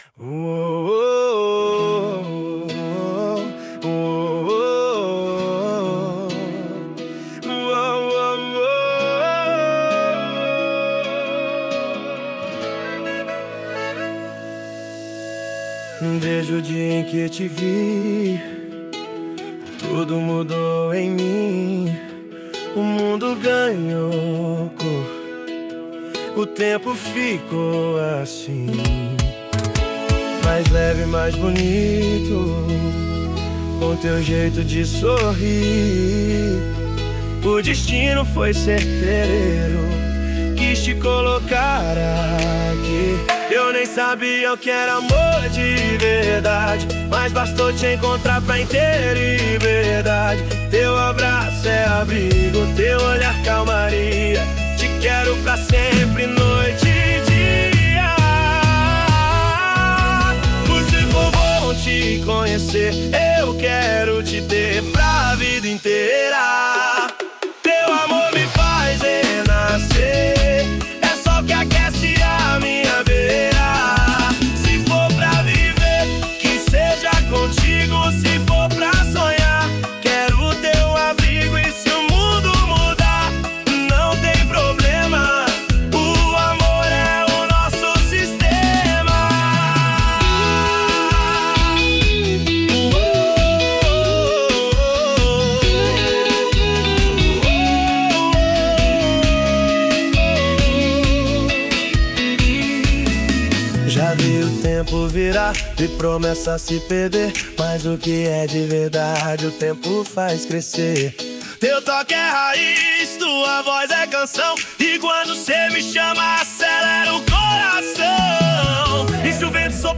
Sertanejo